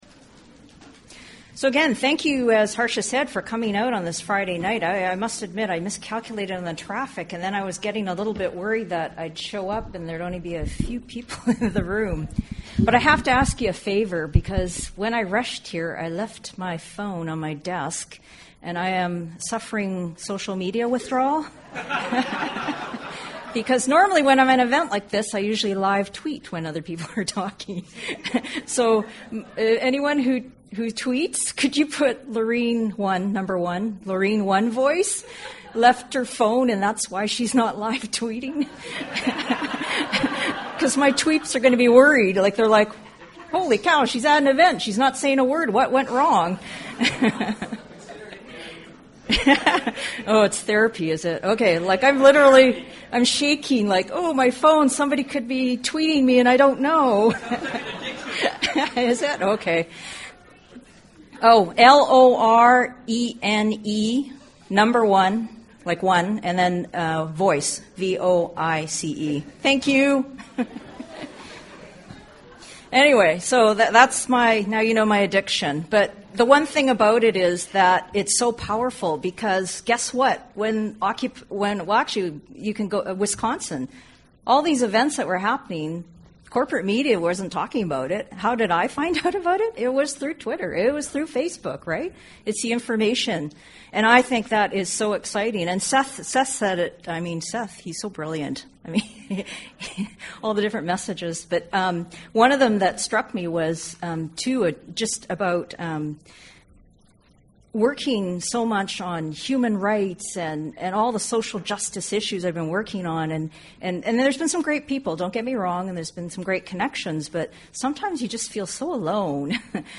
A free public forum on the global Occupy Together movement and tackling issues of systemic inequality, environmental destruction, and government and corporate power.